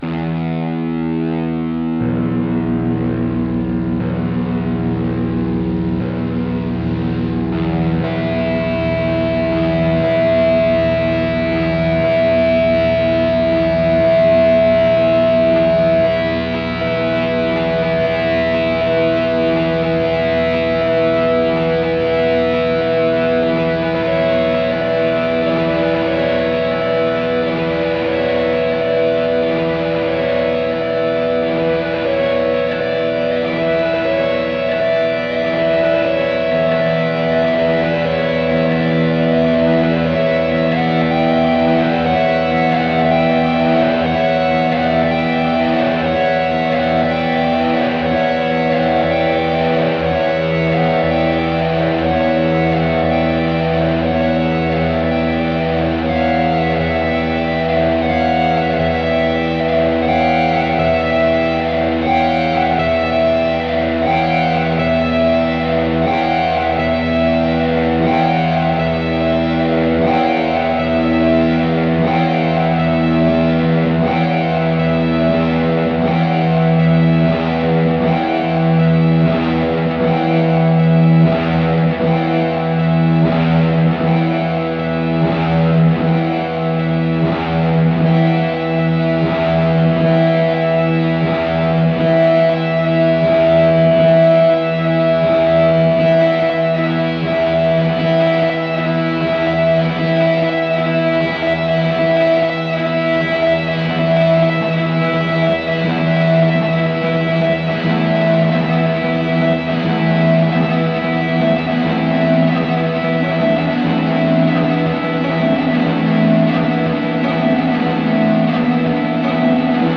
Psychedelic Guitar Ambiance! Reuploading this, as the previous version had terrible mastering.
Positively love the Looper in Ableton~
swirling waves of sound minimalism